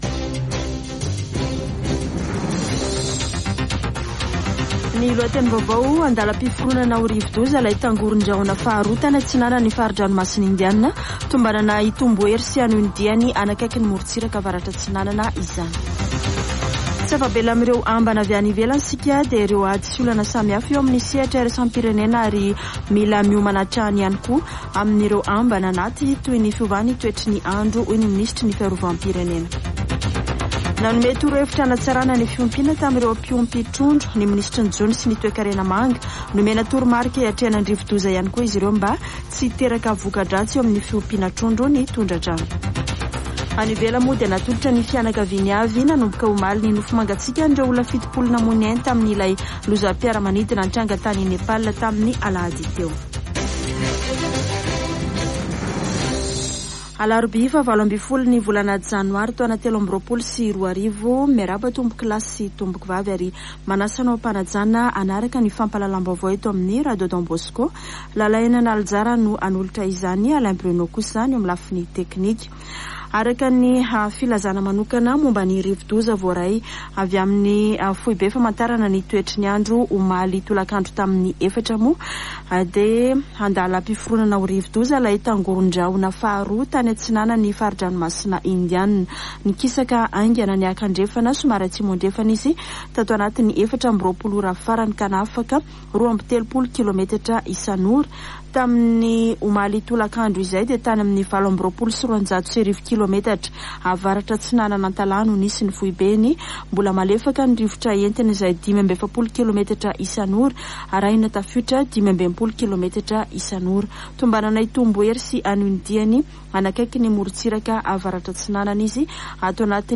[Vaovao maraina] Alarobia 18 janoary 2023